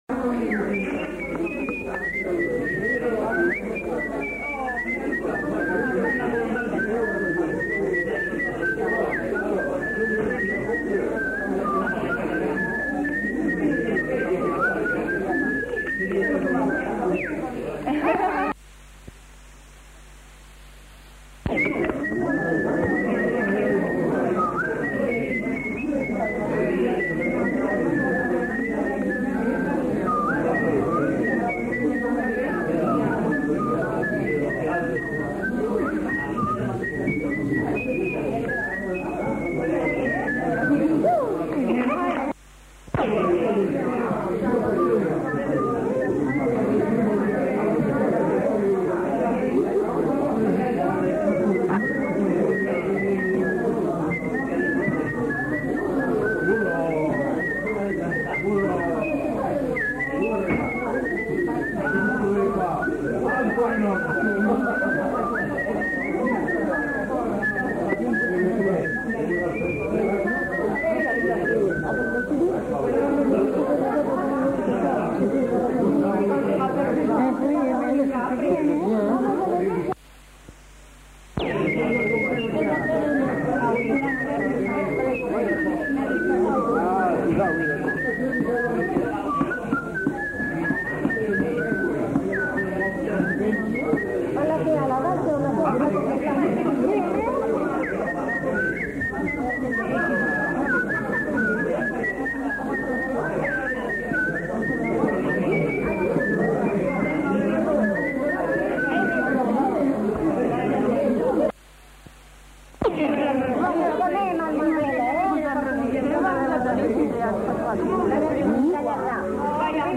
Airs à danser (sifflé)
Aire culturelle : Haut-Agenais
Lieu : Cancon
Genre : chant
Effectif : 1
Type de voix : voix d'homme
Production du son : sifflé
Danse : bourrée ; valse